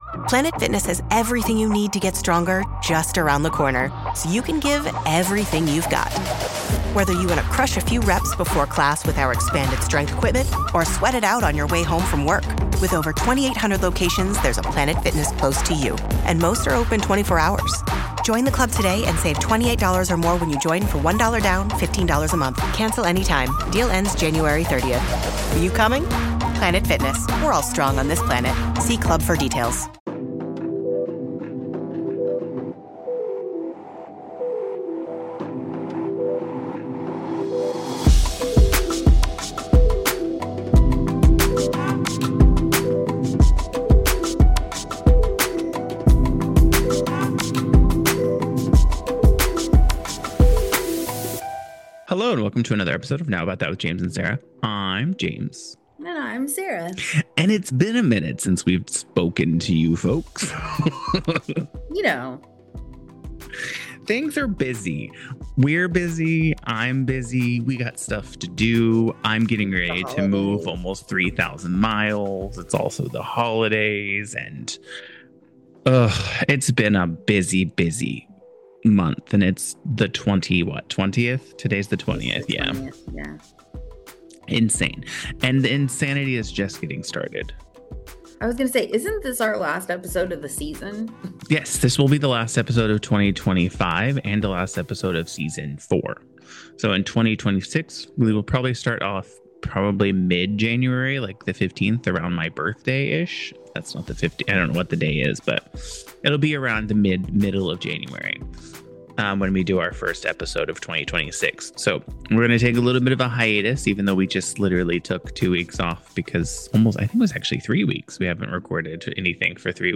Just what the world needs, yet another podcast where two people talk about absolutely nothing, am I right?